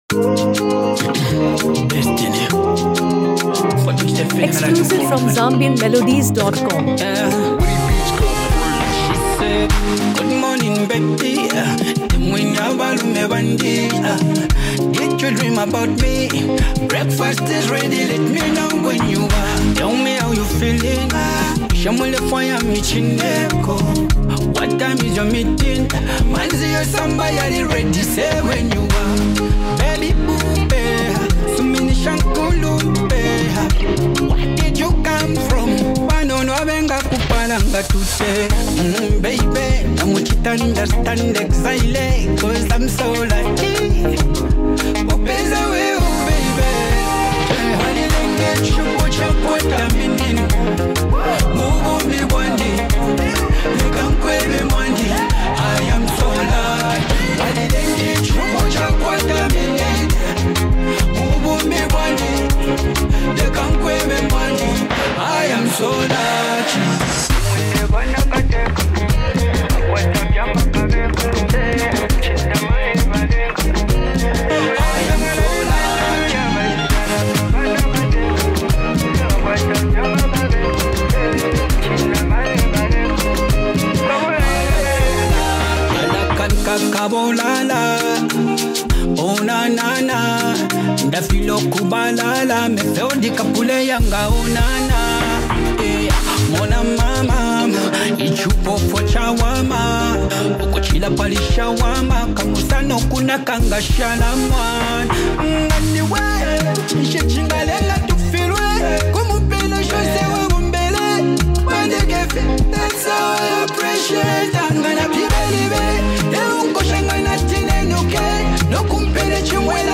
Your New Favorite Afrobeat Anthem
blending Afrobeat rhythms with heartfelt lyrics.
Genre: RnB /Afro-Beats